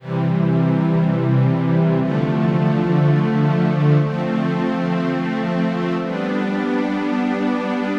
sunrise_ambient.wav